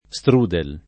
vai all'elenco alfabetico delle voci ingrandisci il carattere 100% rimpicciolisci il carattere stampa invia tramite posta elettronica codividi su Facebook strudel [ S tr 2 del ] (ted. Strudel [ štr 2 udël ]) s. m. (gastron.)